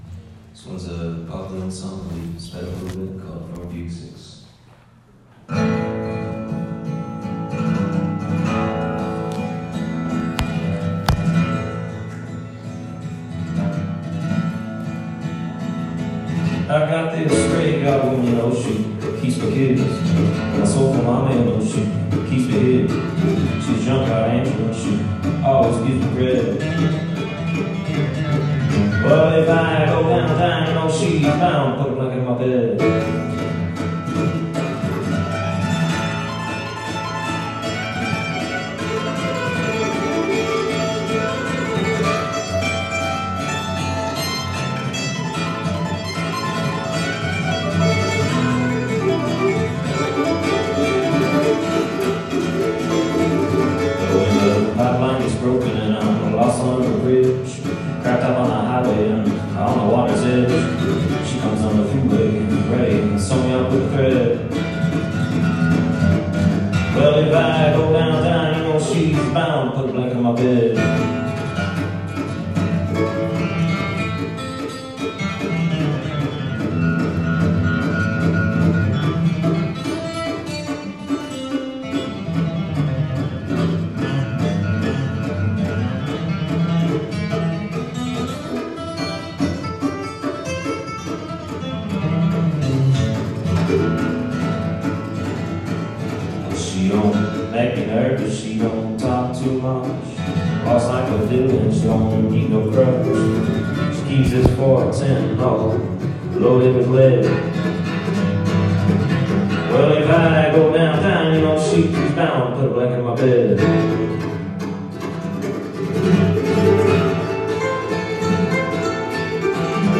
live at Mitchell Park